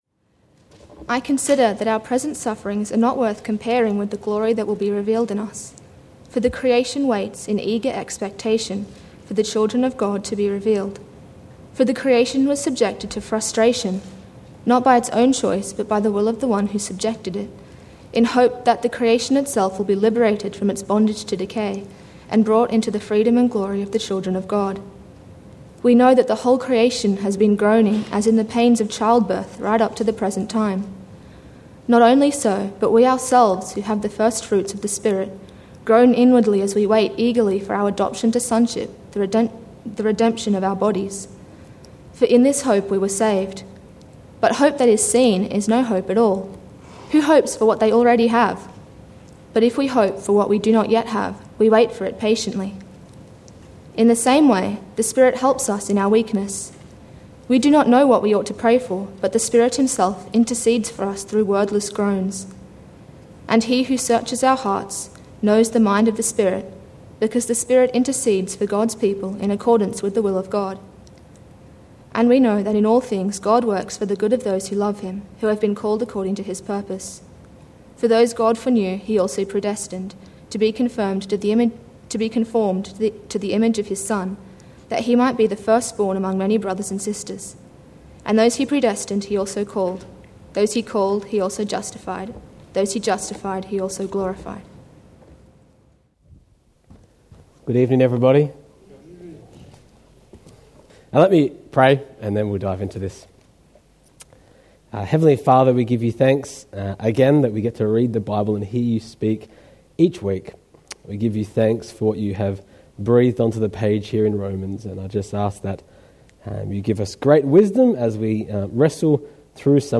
Sermon – Groaning in Hope (Romans 8:18-30)